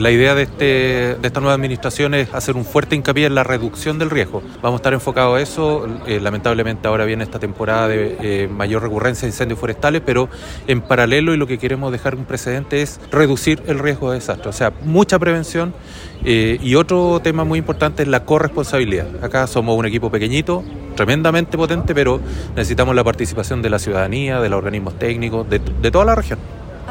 En conversación con Radio Bío Bío, sostuvo que el enfoque de la nueva administración es la “reducción del riesgo de desastres”.